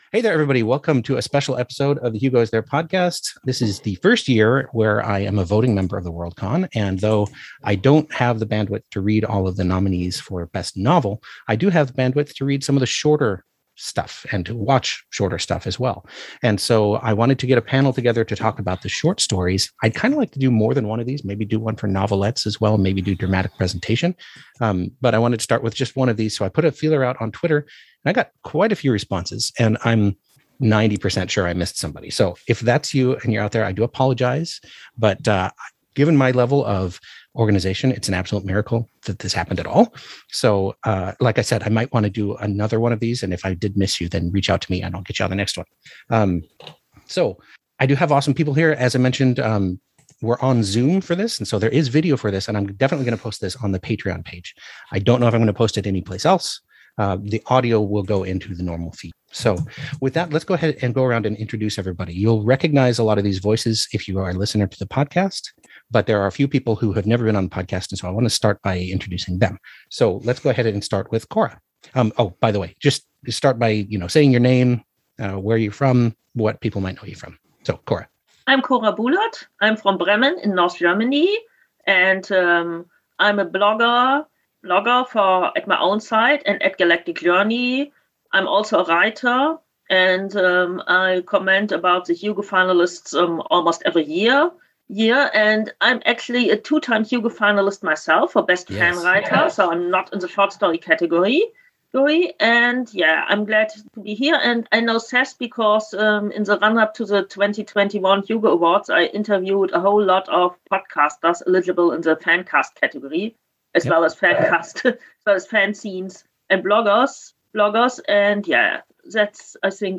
This is the audio-only version of the awesome panel I recently hosted, discussing the 2021 nominees for the Hugo Award for Best Short Story.